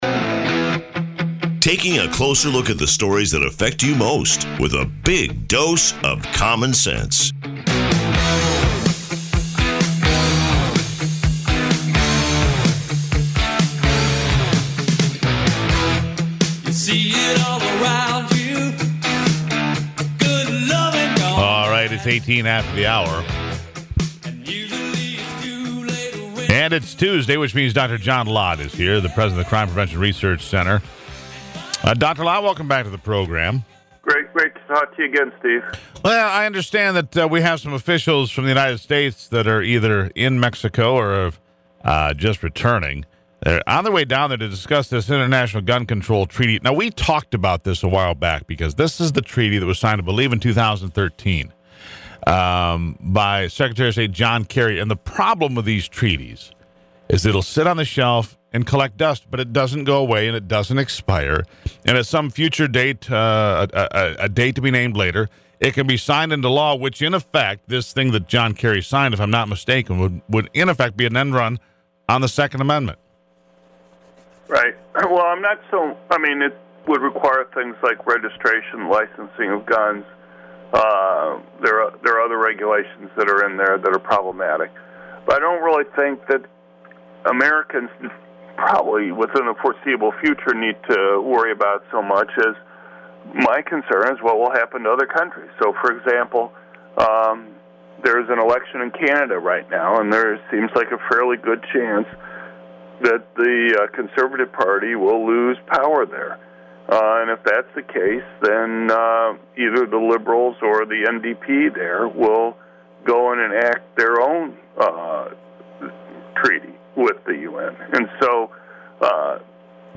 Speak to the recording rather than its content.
CPRC on the Michigan Talk Radio Network to discuss the First Anniversary of the UN Arms Trade Treaty